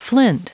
Prononciation du mot flint en anglais (fichier audio)
Prononciation du mot : flint